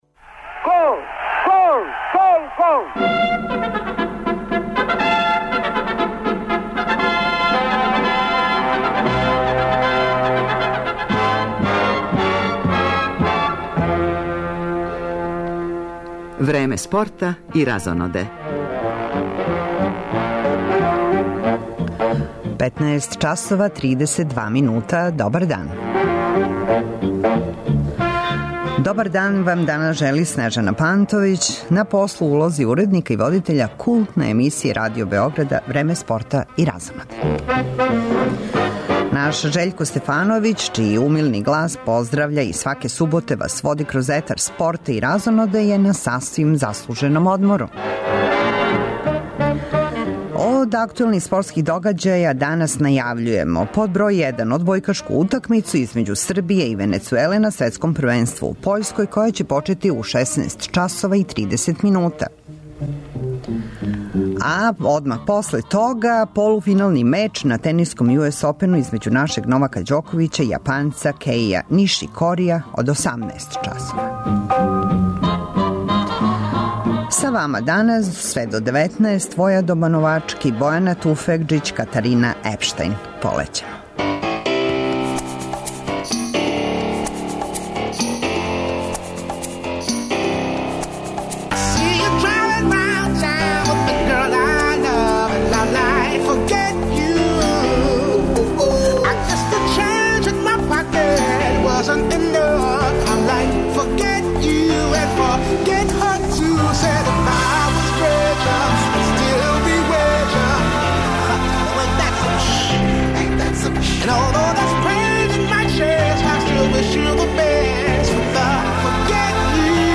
Легендарни голман ФК Партизан Милутин Шошкић присећа се прве утакмице Купа Европеских шампиона одигране 4. септембра 1955. године Спортинг - Партизан, као и чувених дуела комунистичког клуба Партизана и клуба из фашистичке, Франкове Шпаније, Реала.